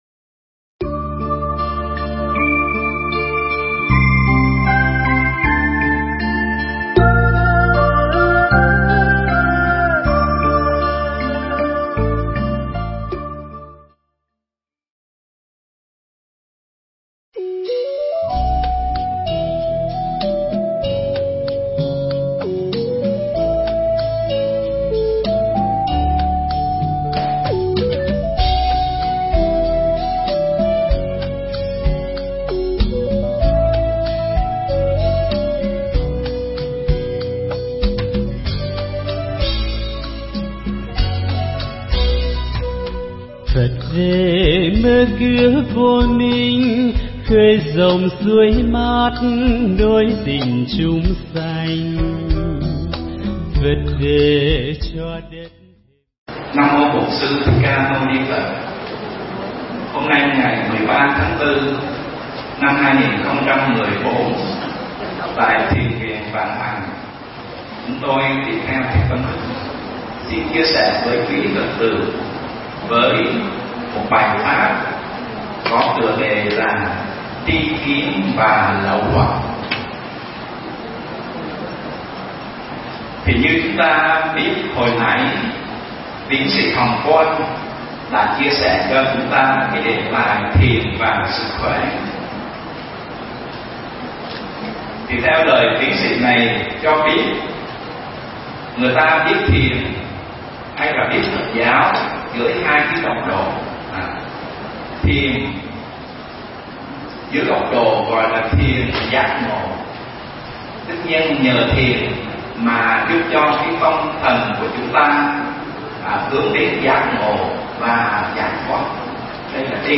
Nghe Mp3 thuyết pháp Tri Kiến Và Lậu Hoặc
Mp3 Pháp thoại Tri Kiến Và Lậu Hoặc